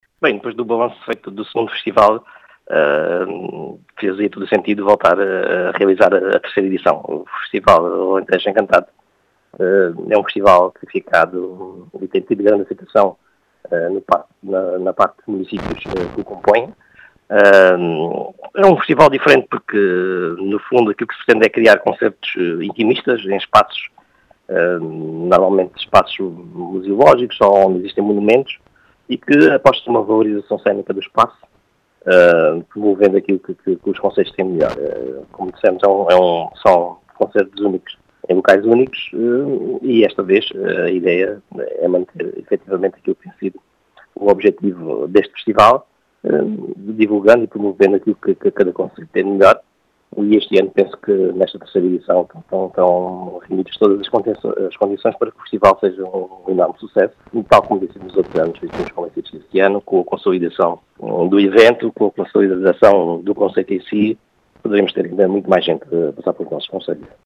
As explicações são do presidente da Associação dosmunicípios do Alentejo Central, João Português.